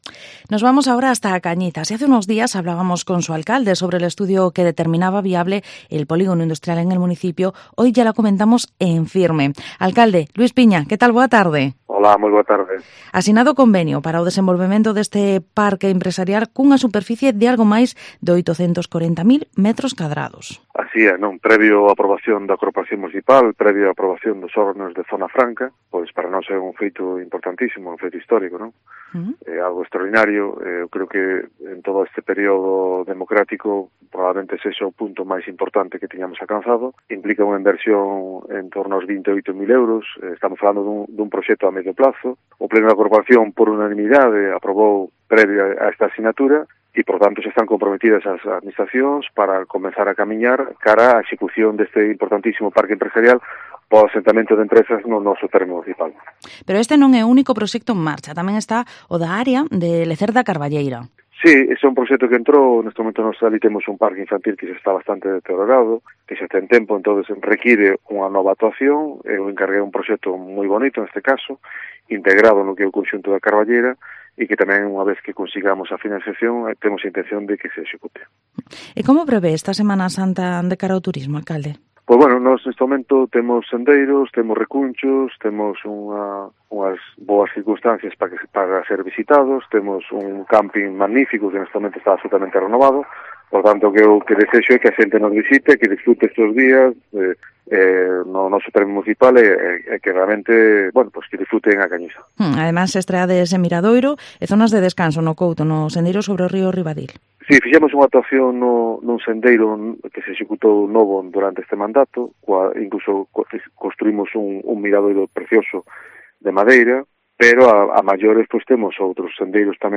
Entrevista al Alcalde de A Cañiza, Luis Piña